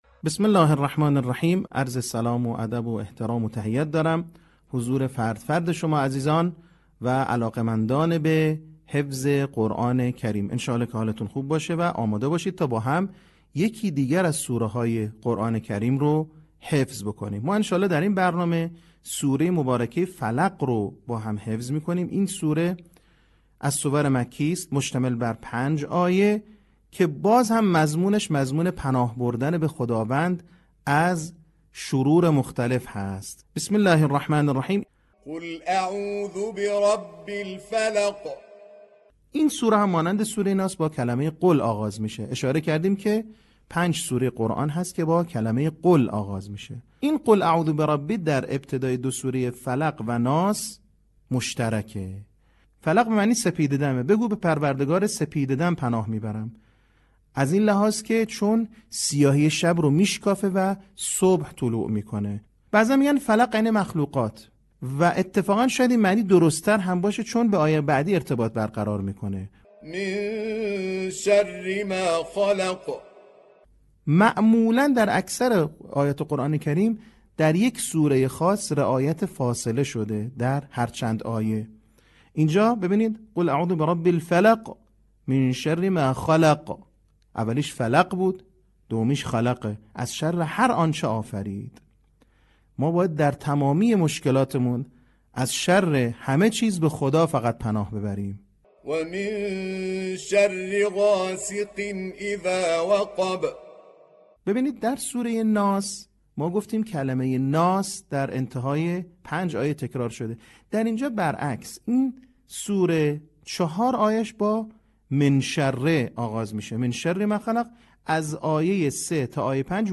صوت | آموزش حفظ سوره فلق